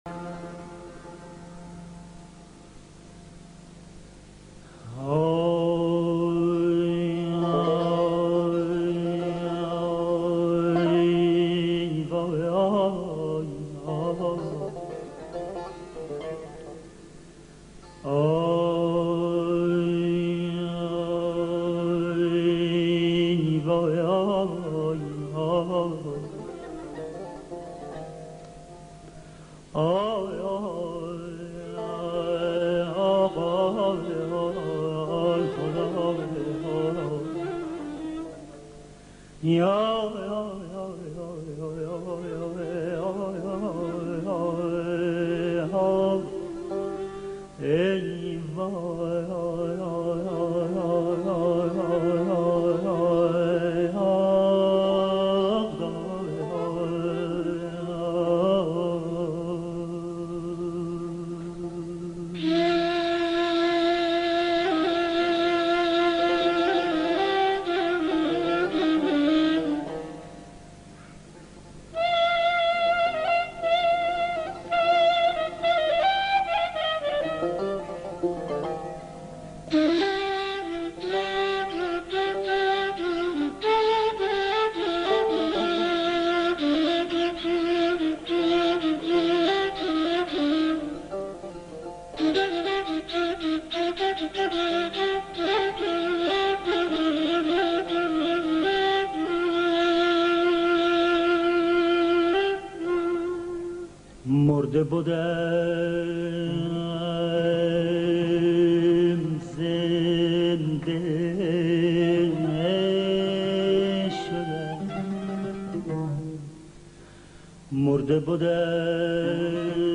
مرده بُدم، زنده شدم با صدای استاد شهرام ناظری